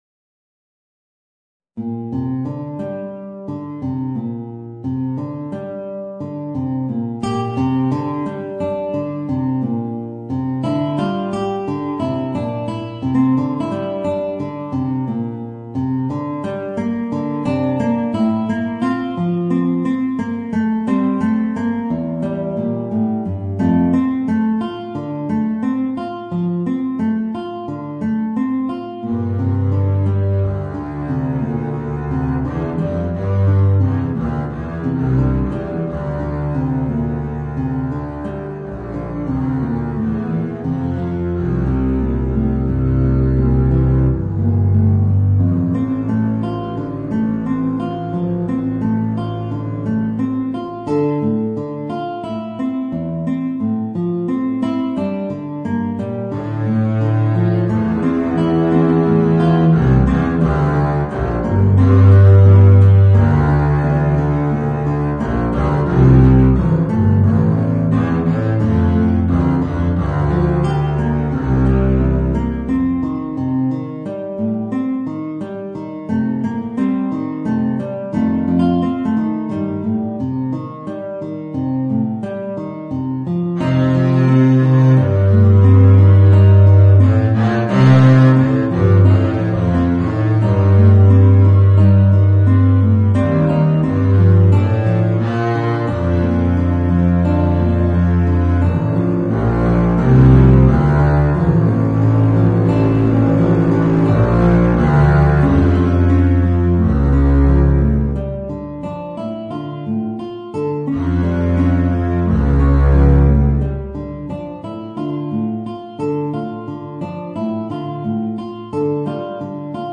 Voicing: Contrabass and Guitar